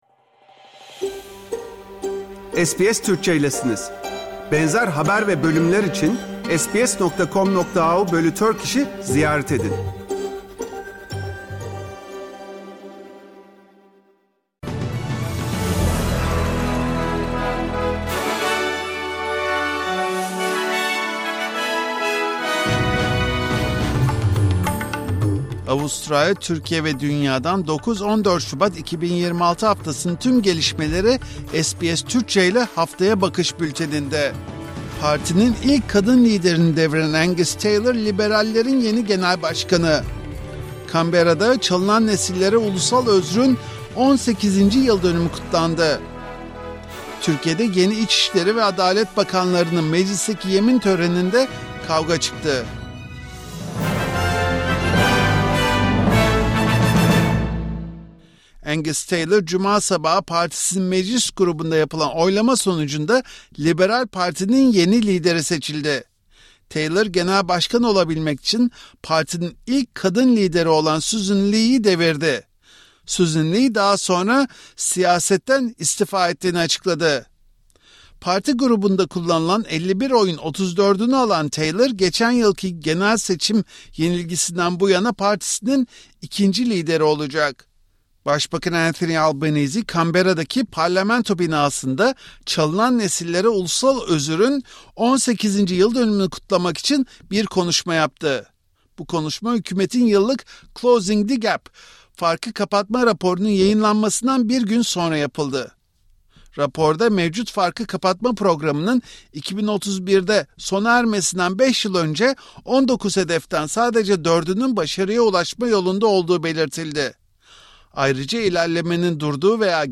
Avustralya, Türkiye ve dünyadan 9 – 14 Şubat 2026 haftasının tüm gelişmeleri SBS Türkçe ile Haftaya Bakış bülteninde.